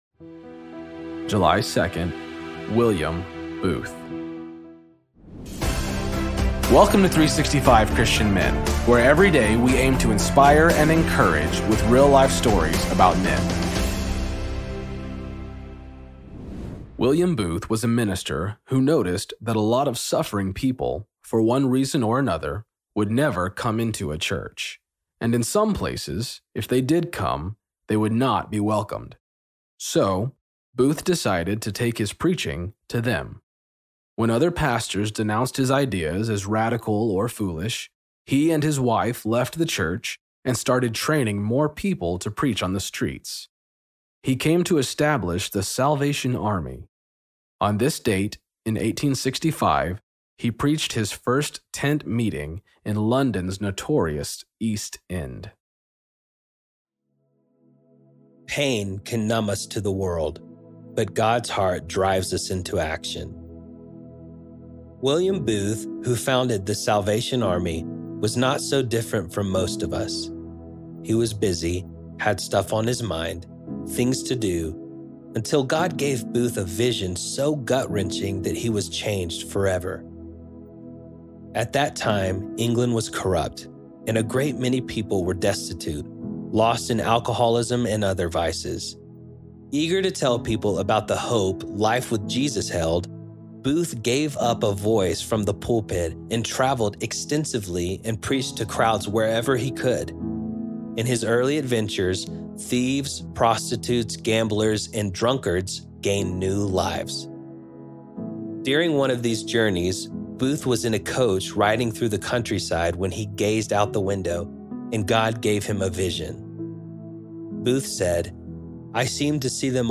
Story read by
Introduction read by